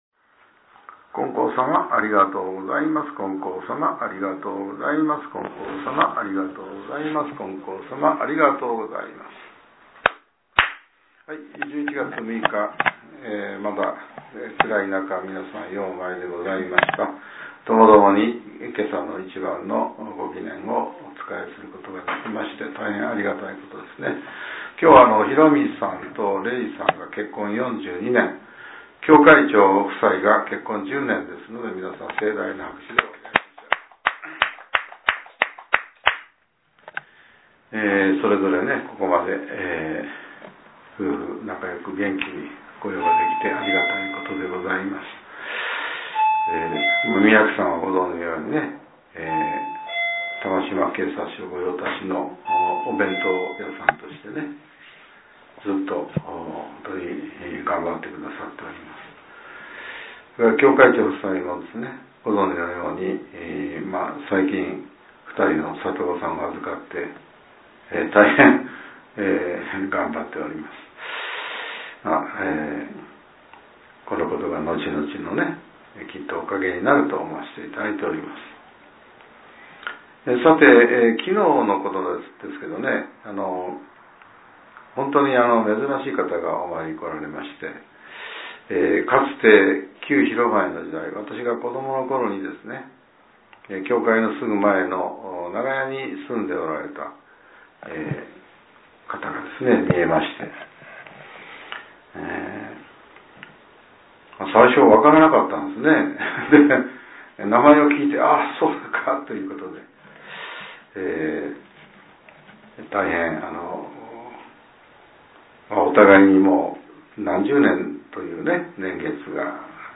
令和７年１１月６日（朝）のお話が、音声ブログとして更新させれています。